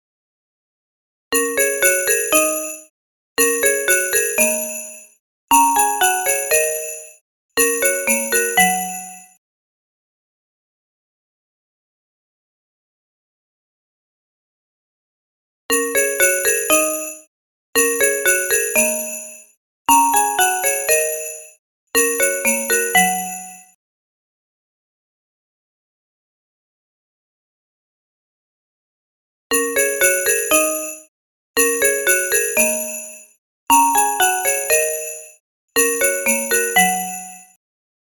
קבצי שמע לתרגול (שירי נגינה עליזים ומלאי מוטיבציה) –
Track-10-Bells.mp3